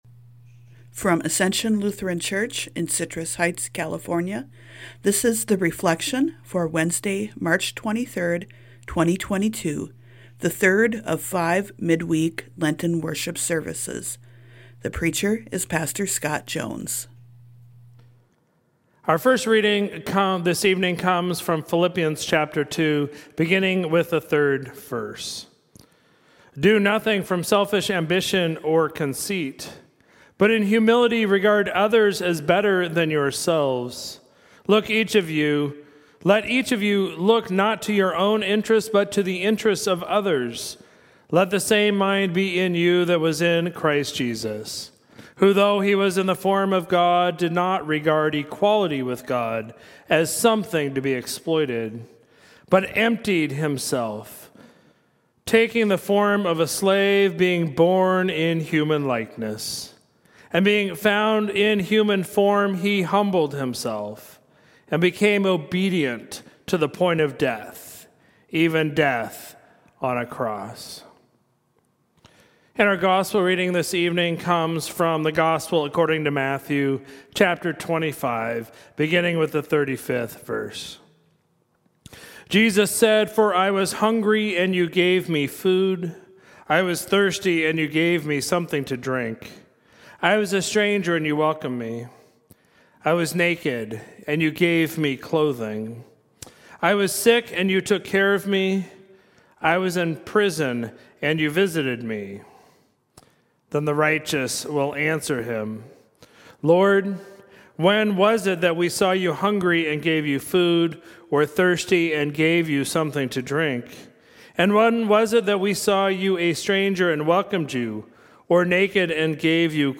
Sermons at Ascension